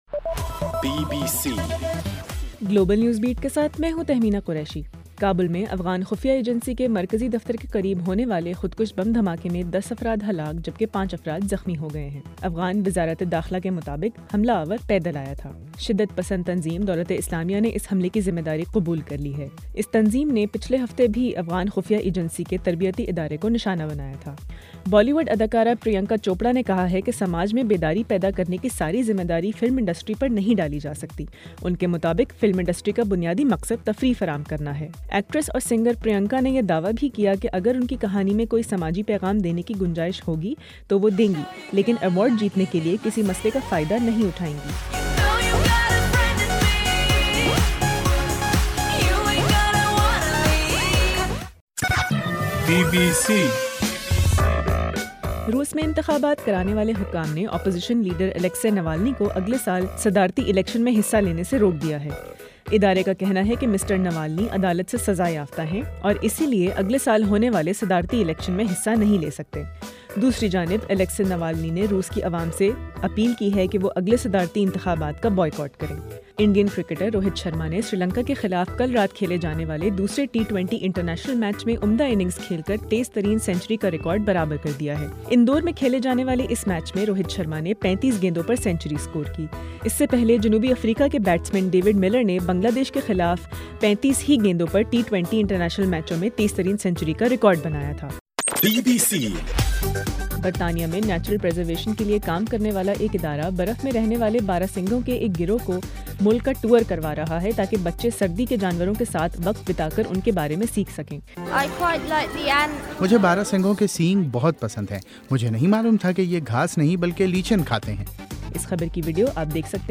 گلوبل نیوز بیٹ بُلیٹن اُردو زبان میں رات 8 بجے سے صبح 1 بجے ہرگھنٹےکے بعد اپنا اور آواز ایفایم ریڈیو سٹیشن کے علاوہ ٹوئٹر، فیس بُک اور آڈیو بوم پر